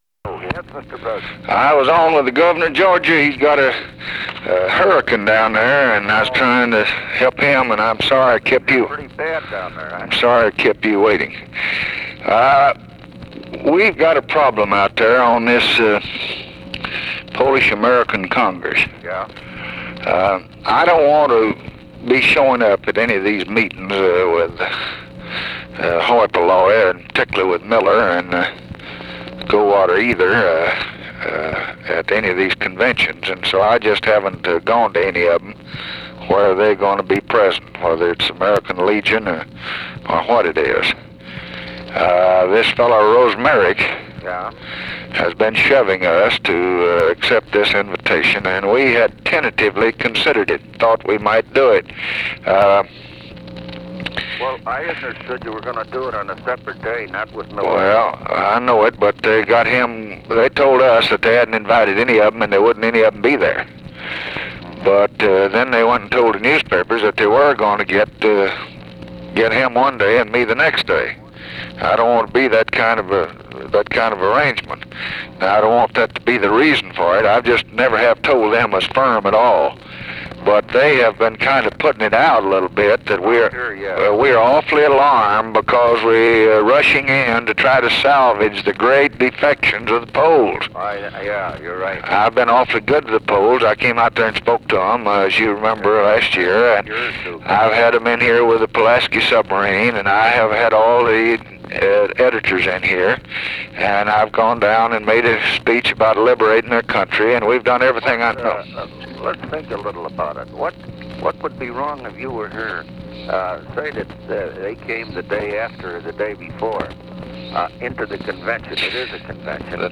Conversation with RICHARD DALEY, September 10, 1964
Secret White House Tapes